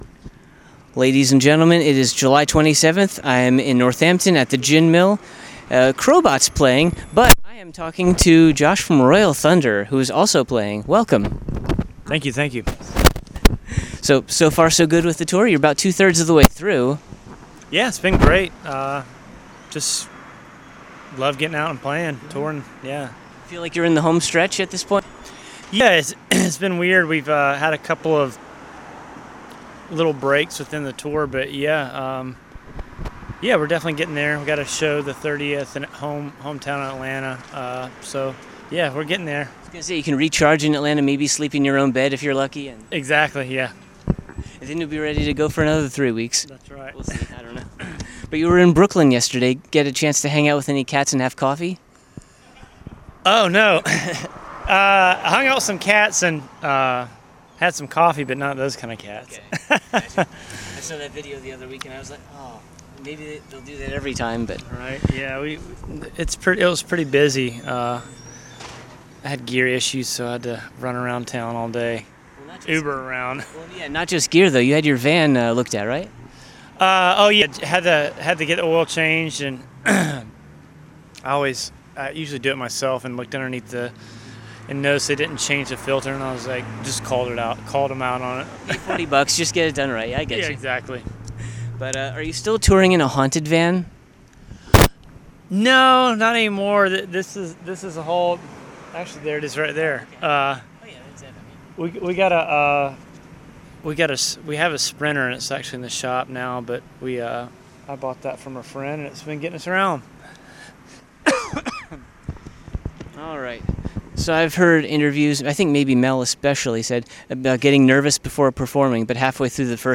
Exclusive Interview: Royal Thunder at the Gin Mill (Ep 80)
80-interview-royal-thunder.mp3